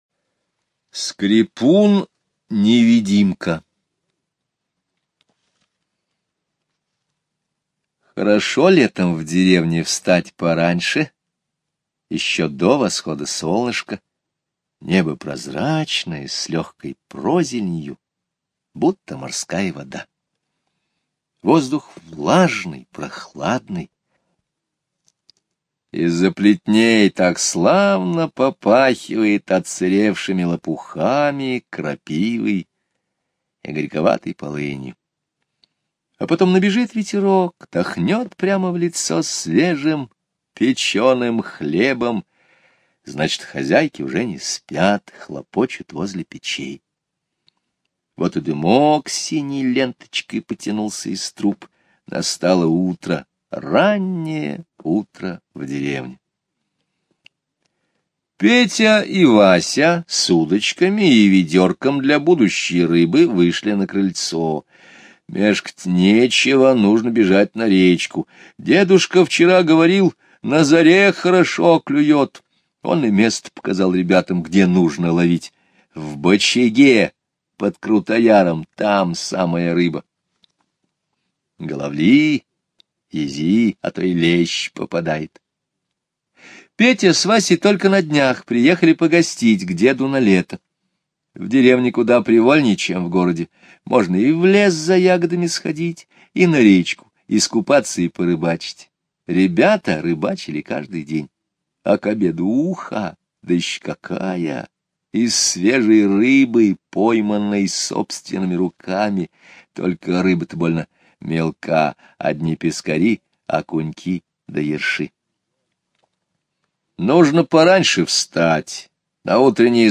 Слушайте Скрипун-невидимка - аудио рассказ Скребицкого Г. Однажды летним утром ребята, приехавшие в гости к дедушке, пошли на рыбалку.